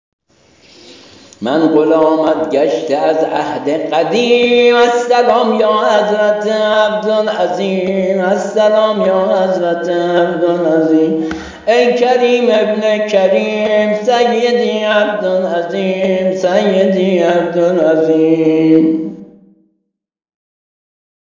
زمزمه‌ی ذکر محدث‌اهلبیت‌